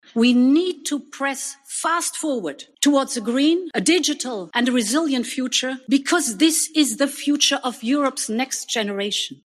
Avete appena ascoltato un passaggio del discorso al Parlamento europeo pronunciato dalla Presidente della Commissione europea Ursula von der Leyen.